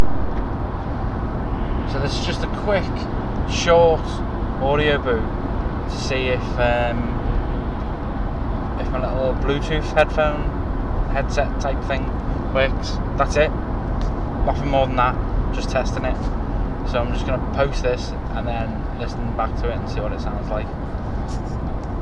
testing a Jawbone icon with audiovox